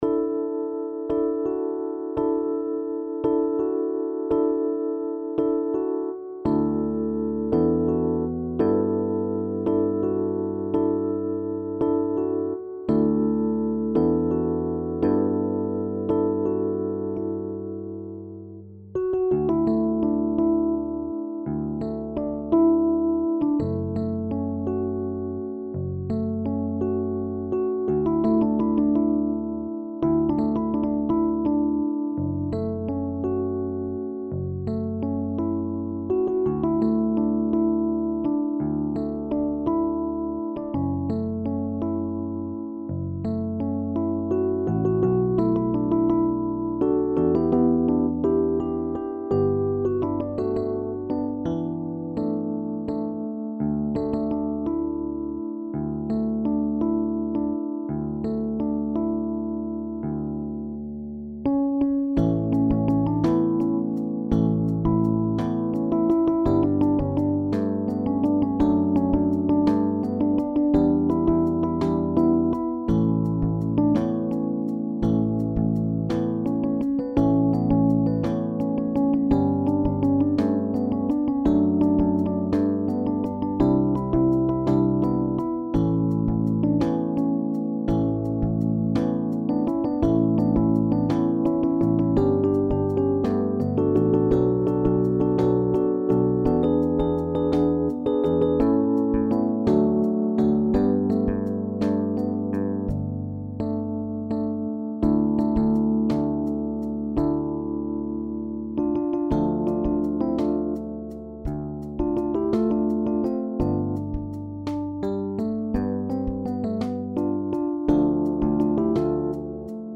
SSATTB